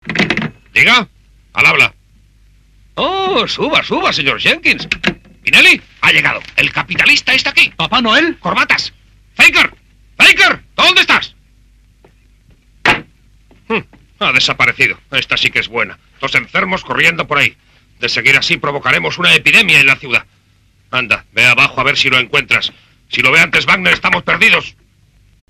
La pista doblada en castellano -en verdadero audio monoaural 2.0- es similar en calidad a la de la versión original. No hay ruido de fondo y, aunque la música distorsiona algo, los diálogos no suenan mal.
muestra del doblaje.
DD 2.0 mono Castellano